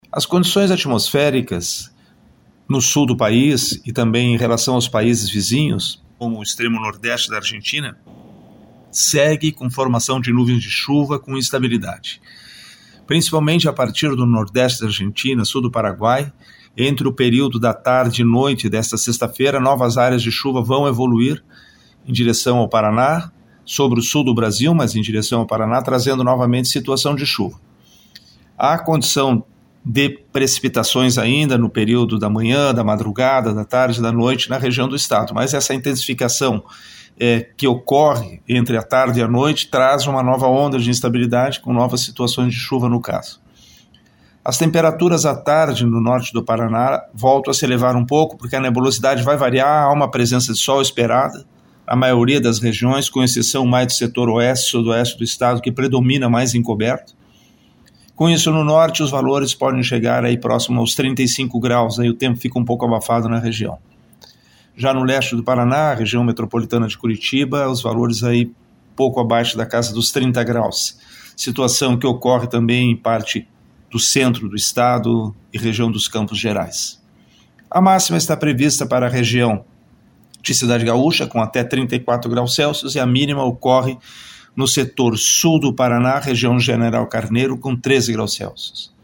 Previsão do tempo